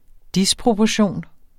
Udtale [ ˈdispʁobʌˌɕoˀn ]